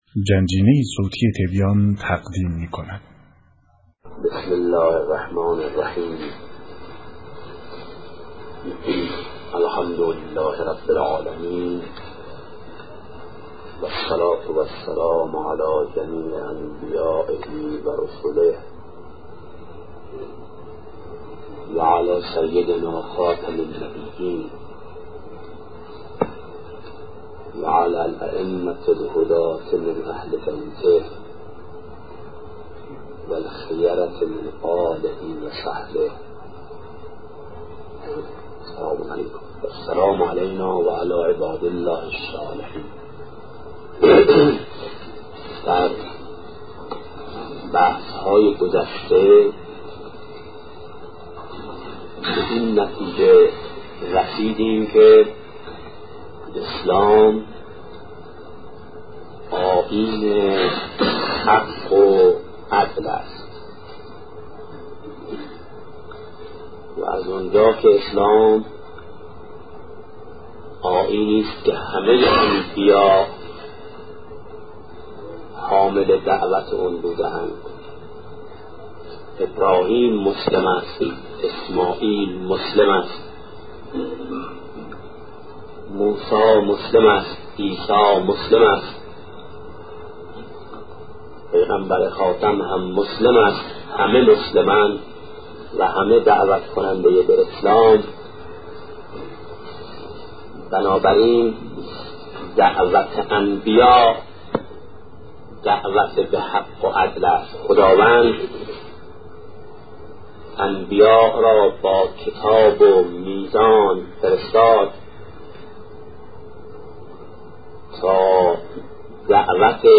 سخن شهید بهشتی- اسلام، دین حق و عدل-بخش‌اول